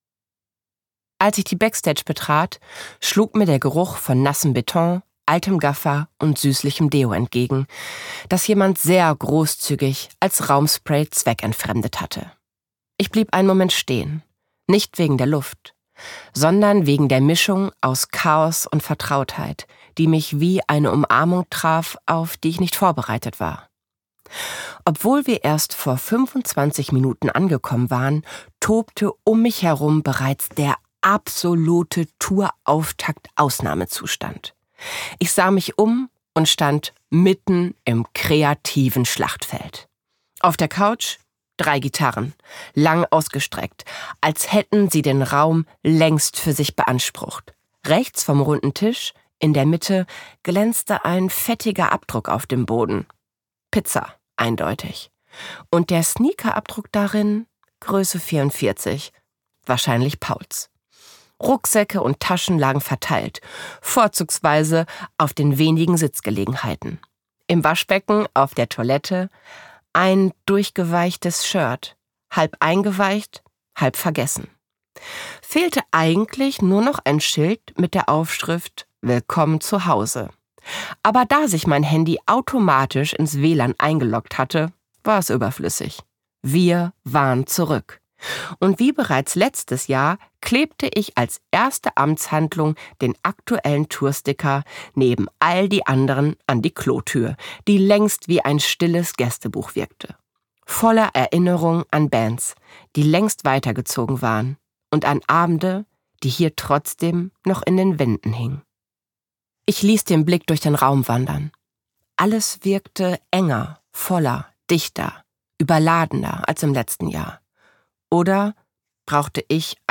Das Hörbuch Das Mädchen aus der 1. Reihe – Das große Comeback ist die bewegende Fortsetzung des Erfolgsromans von Jana Crämer und ein emotionales Roman-Hörbuch über Selbstannahme, Körperbild, Liebe und den Mut, sich selbst nicht länger zu verleugnen.
Das Mädchen aus der 1. Reihe Gelesen von: Jana Crämer